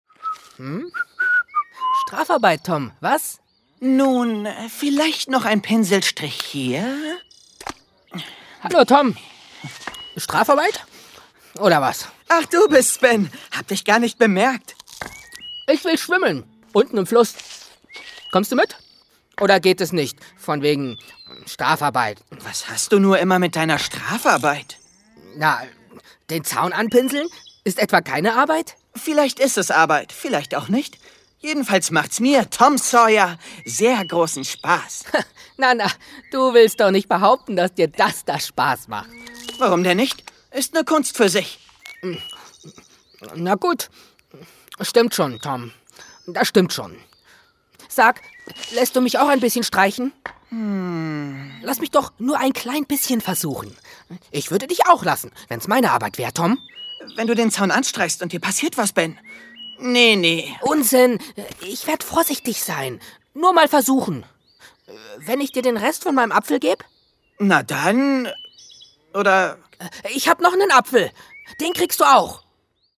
Hörspiel „Tom Sawyer“
Rolle: Ben Rogers
Hoerprobe-Tom-Sawyer_RolleBen.mp3